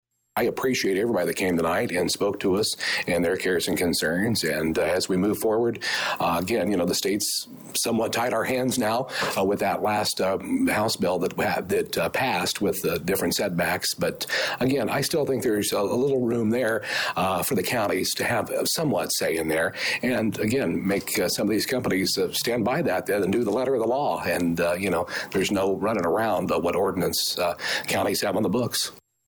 County Board Chairman Larry Baughn reacted to the citizen comments…